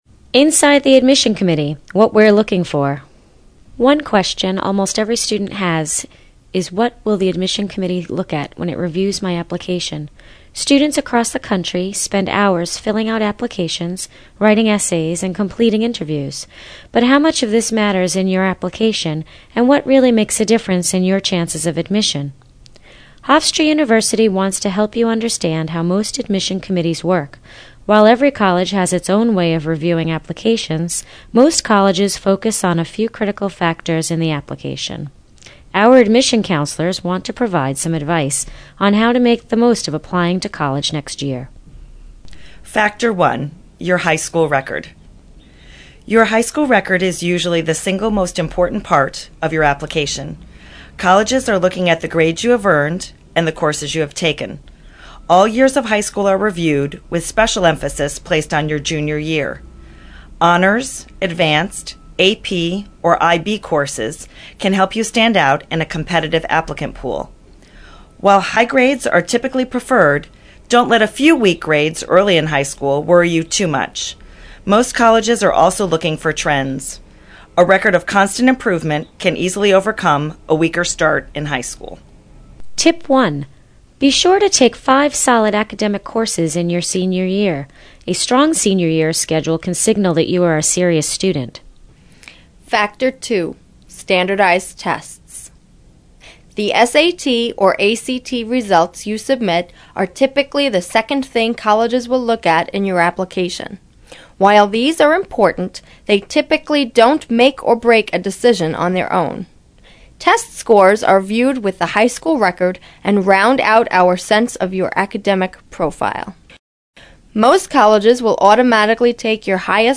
Hofstra understands that a busy high-school student doesn't have time to slow down, so we've made it easy for you to learn more about the admission process. Download these podcasts, recorded by our admission counselors, and listen to them on your schedule.